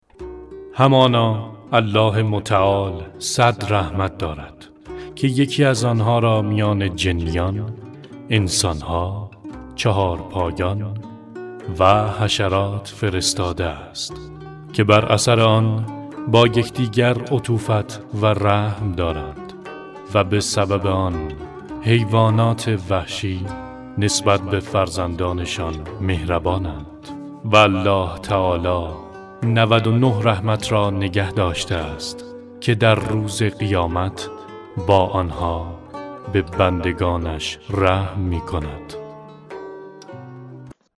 Male
Adult